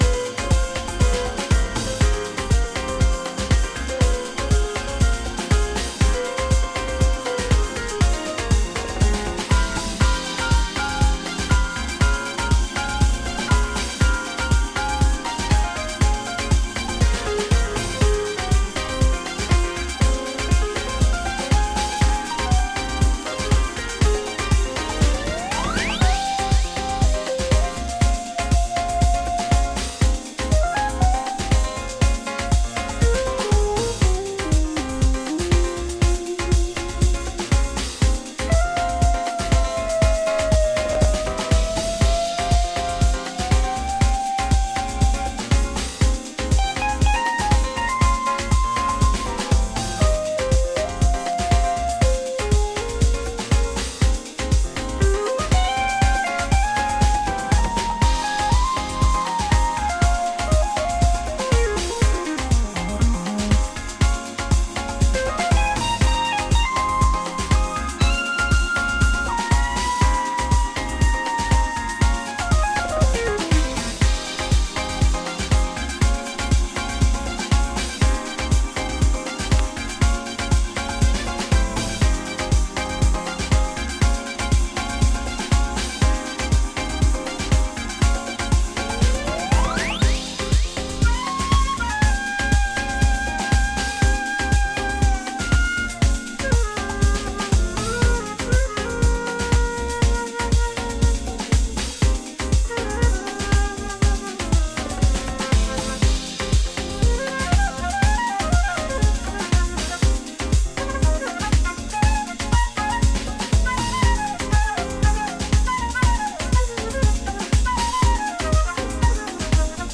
躍進の止まらない彼からエネルギーを貰える、温かなジャジー・ディープハウス集です。